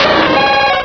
pokeemerald / sound / direct_sound_samples / cries / suicune.aif